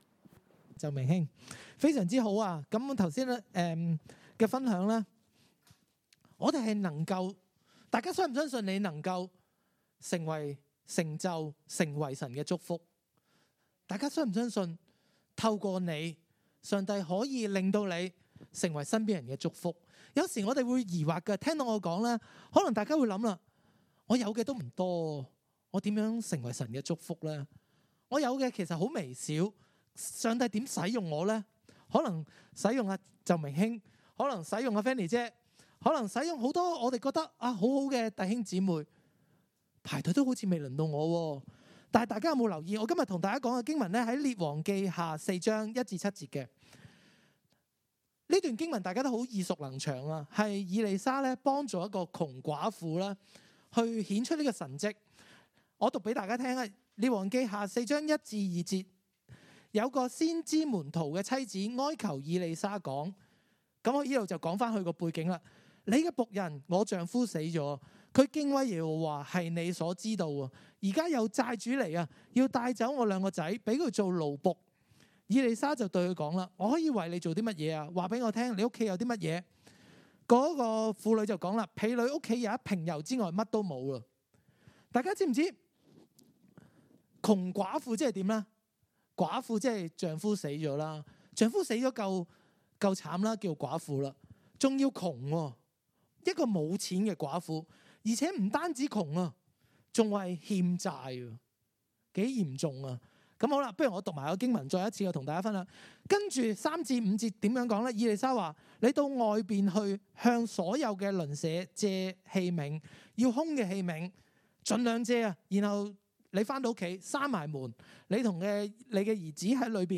2021年12月12日晚堂信息